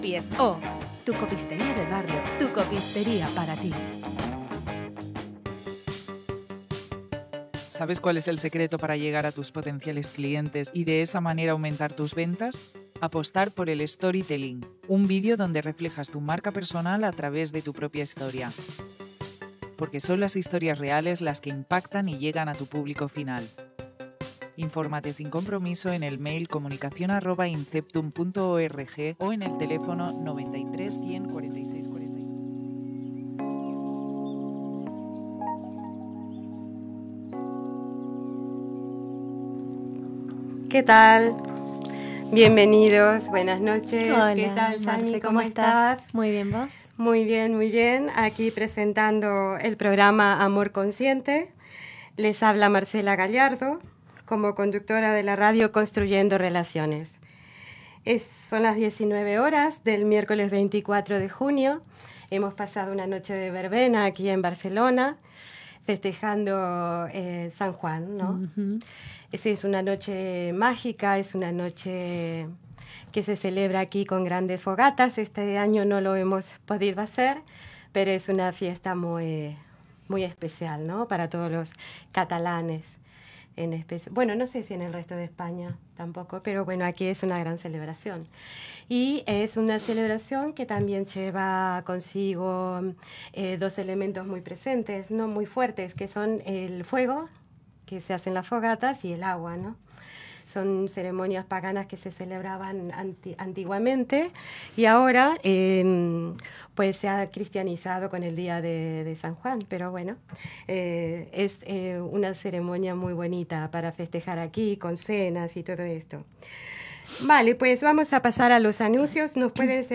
Publicitat, presentació del programa amb la identificació de la ràdio, comentari de la nit de Sant Joan, adreces d'Internet des d'on es pot escoltar el programa
Divulgació